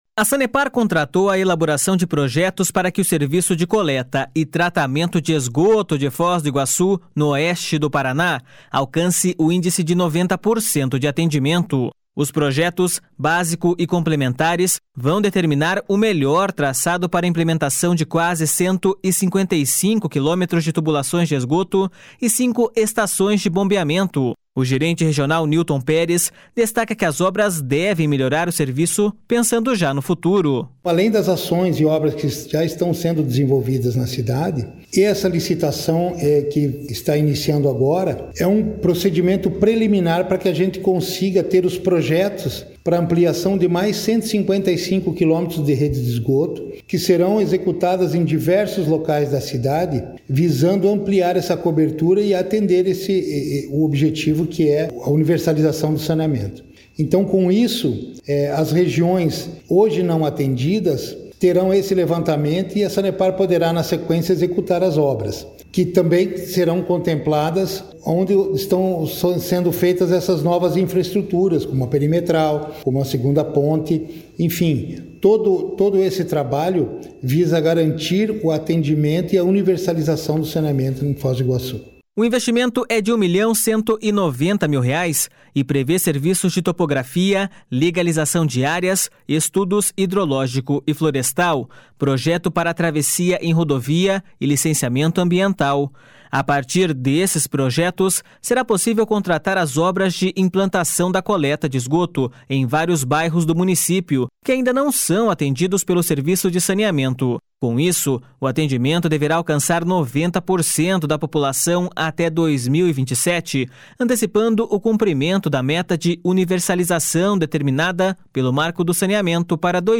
Com isso, o atendimento deverá alcançar 90% da população até 2027, antecipando o cumprimento da meta de universalização determinada pelo marco do saneamento para 2033. (Repórter